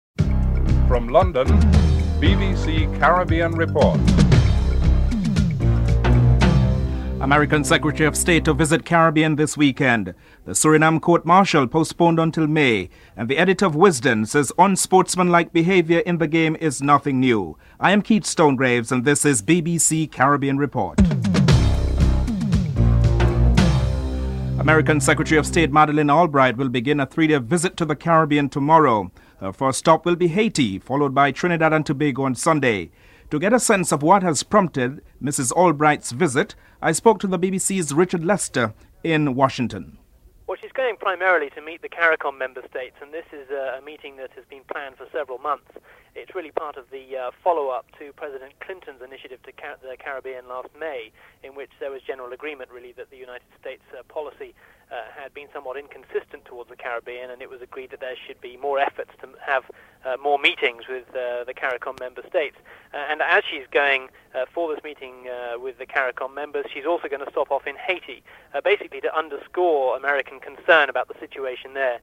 7. Recap of top stories (14:55-15:15)